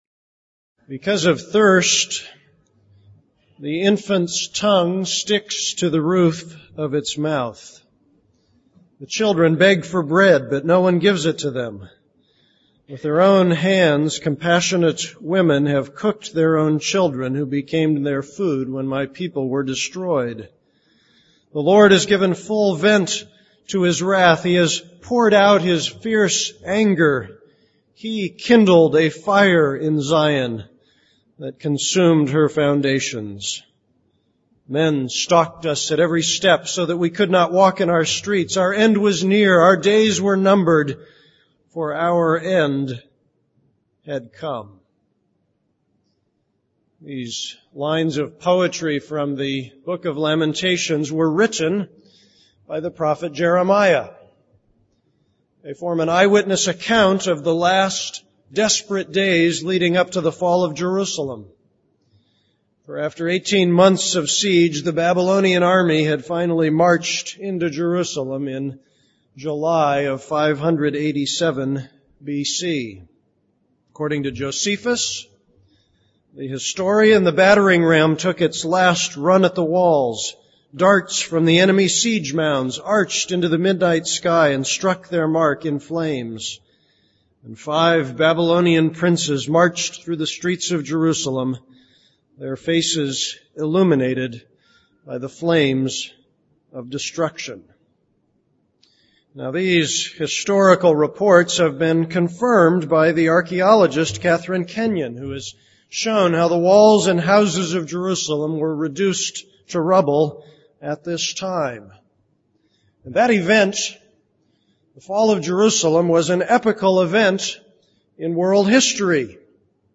This is a sermon on Jeremiah 39:1-18.